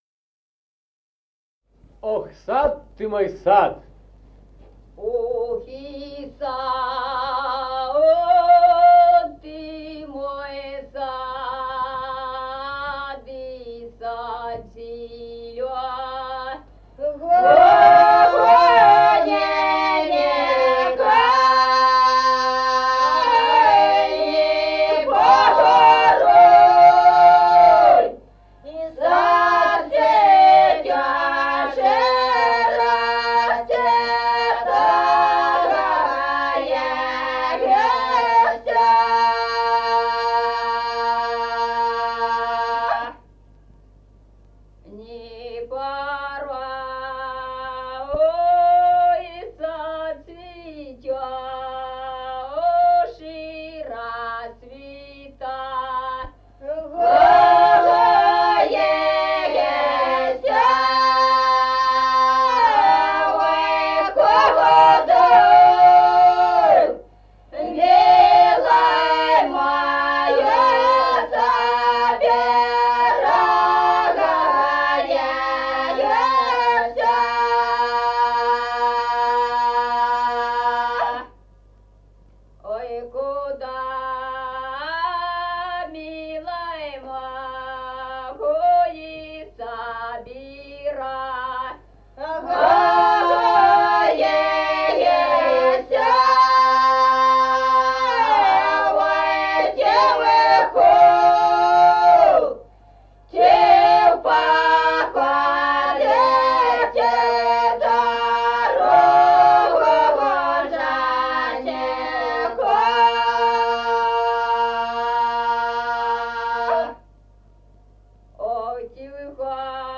Народные песни Стародубского района «Ох, сад, ты мой сад», воинская.
1953 г., с. Мишковка.